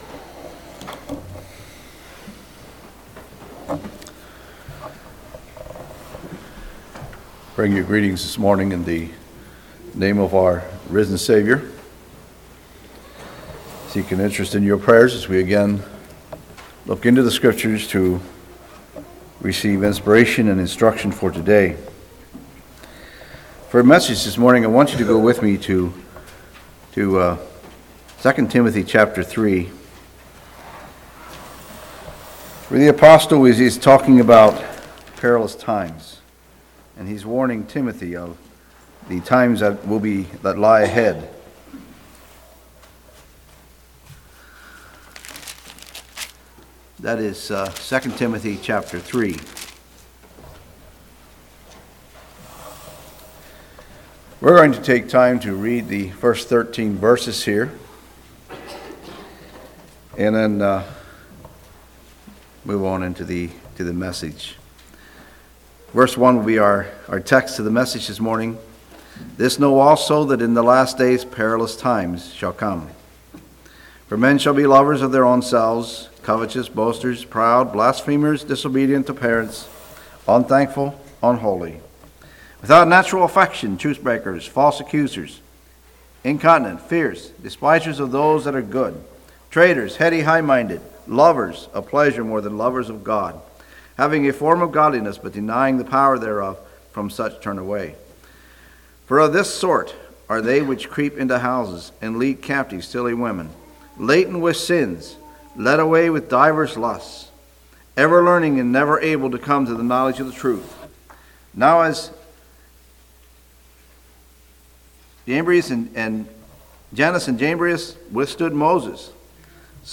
2019 Sermon ID